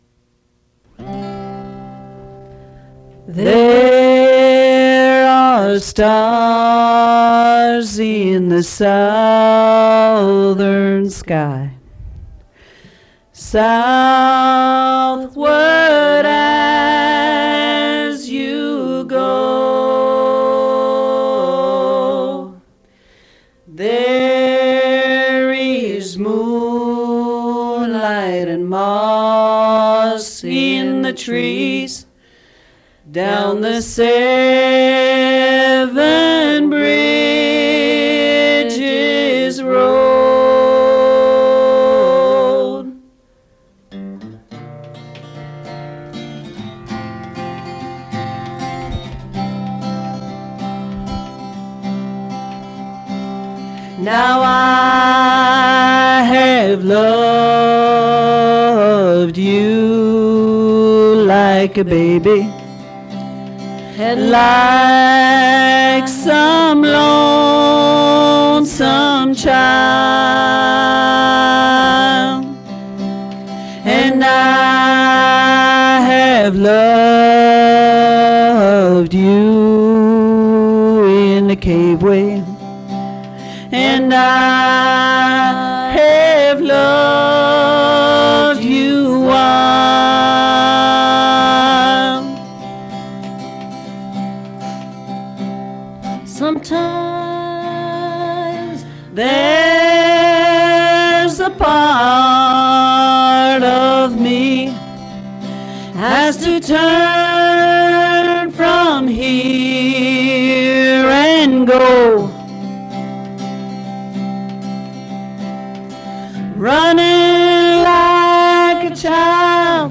Fait partie de Acoustic reinterpretation of rock music